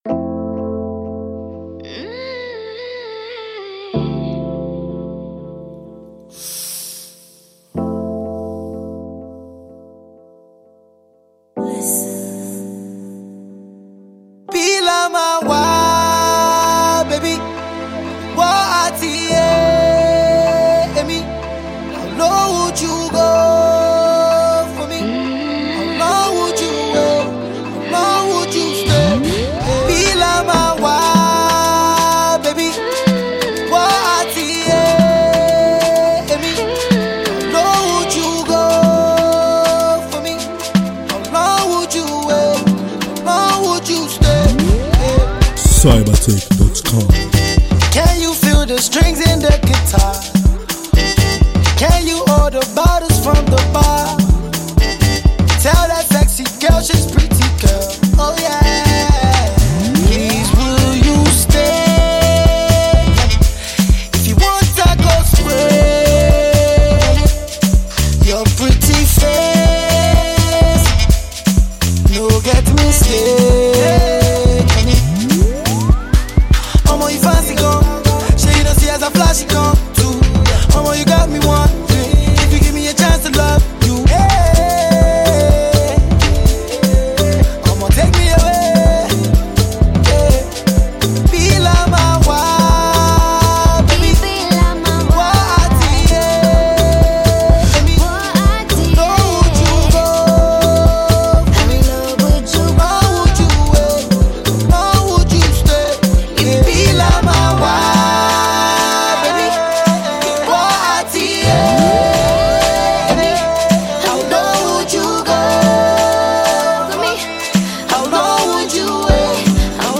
The long awaited collaboration from Afrobeats superstar